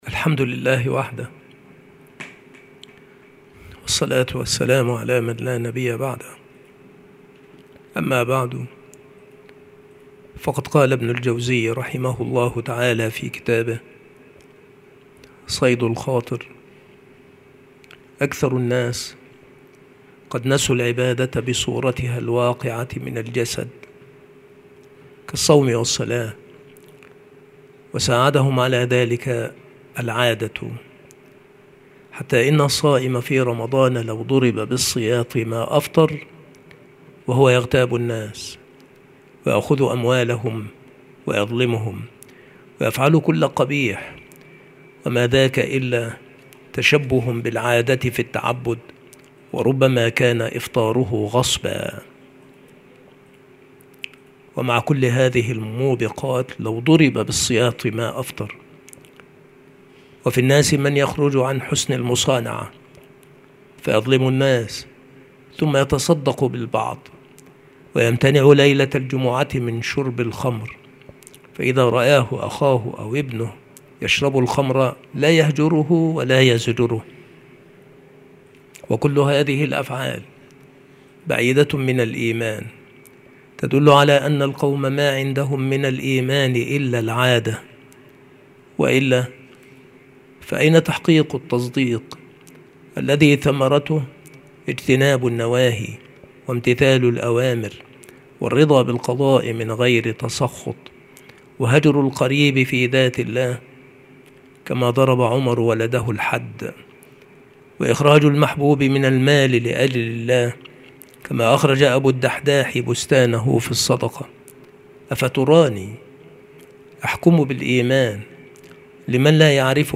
مكان إلقاء هذه المحاضرة المكتبة - سبك الأحد - أشمون - محافظة المنوفية - مصر عناصر المحاضرة : فصل : تحول العبادات إلى عادات.